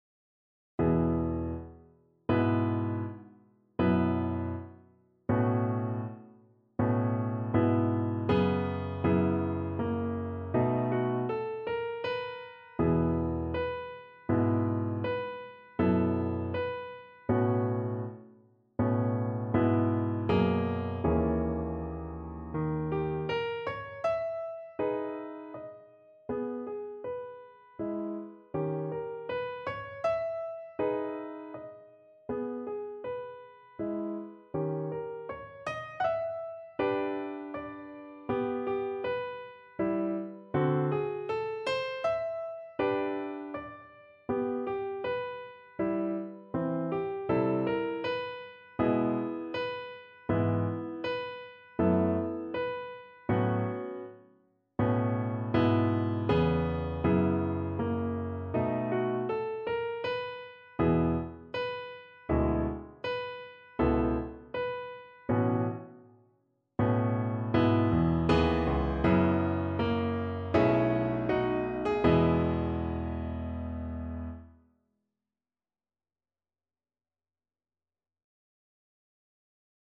No parts available for this pieces as it is for solo piano.
4/4 (View more 4/4 Music)
Andante con moto =80 (View more music marked Andante con moto)
E major (Sounding Pitch) (View more E major Music for Piano )
Piano  (View more Intermediate Piano Music)
Classical (View more Classical Piano Music)